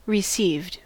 Ääntäminen
Ääntäminen US : IPA : [rɪˈsiːvd] Tuntematon aksentti: IPA : /ɹɪˈsiːvd/ Haettu sana löytyi näillä lähdekielillä: englanti Käännöksiä ei löytynyt valitulle kohdekielelle.